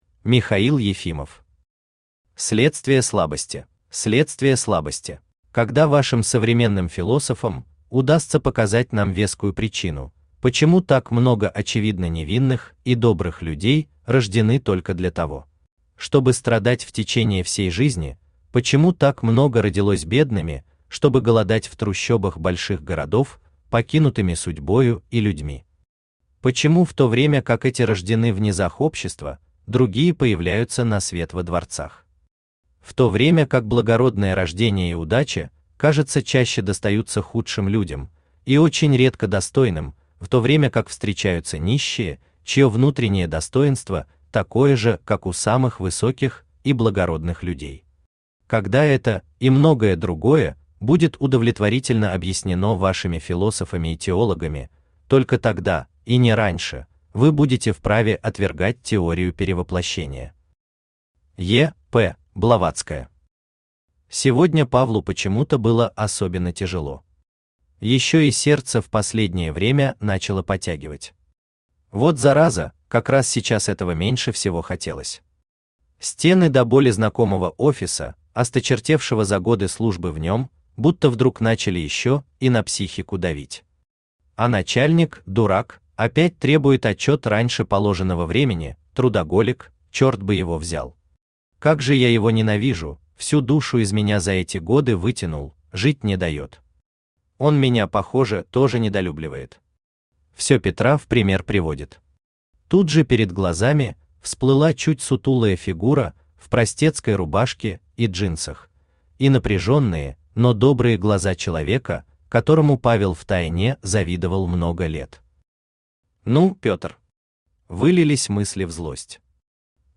Аудиокнига Следствие слабости | Библиотека аудиокниг
Прослушать и бесплатно скачать фрагмент аудиокниги